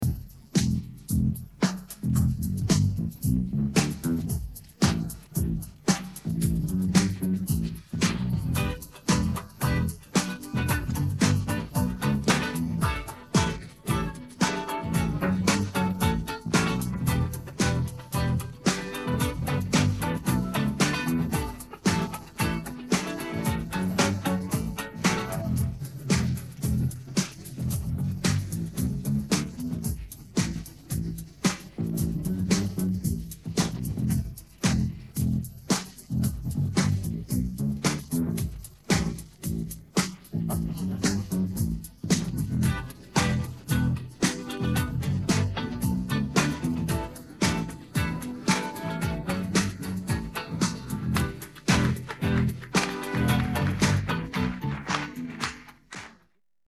Música de fundo